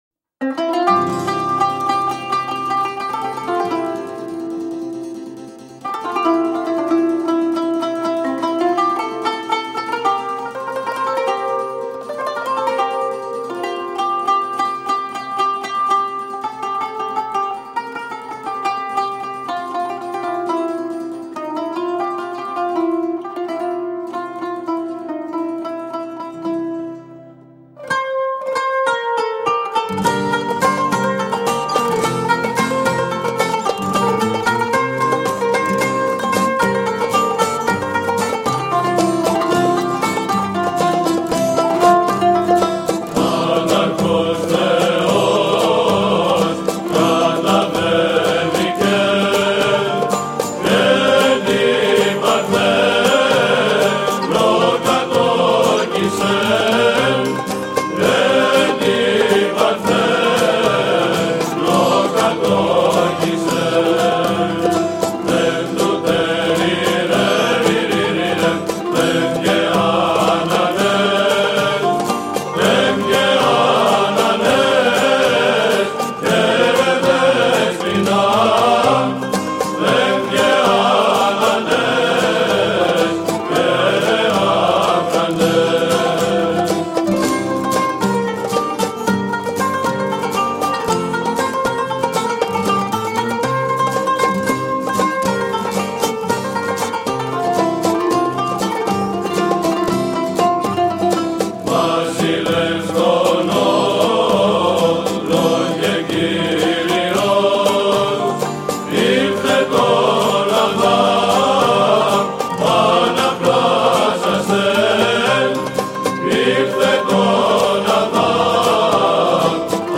“Βυζαντινά” κάλαντα